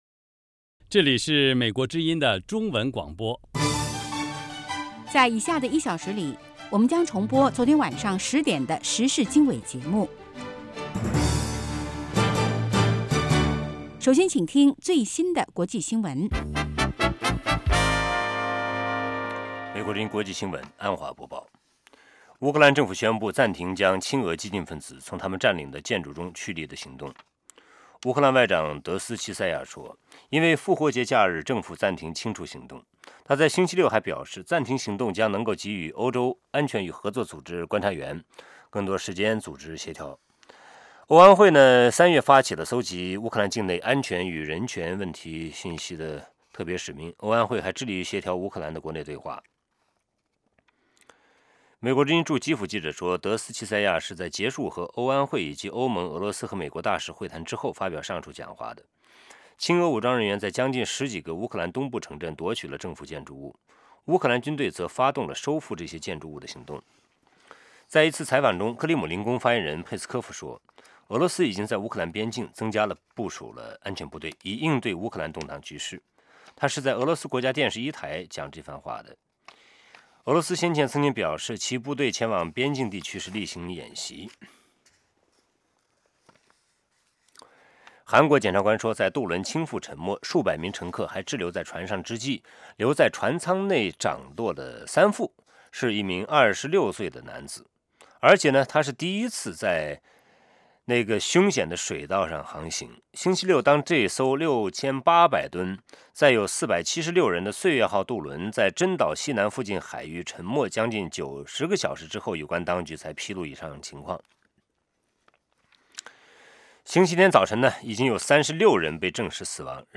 周一至周五：国际新闻 时事经纬(重播) 周六：时事经纬 听众热线 (重播) 北京时间: 上午8点 格林威治标准时间: 0000 节目长度 : 60 收听: mp3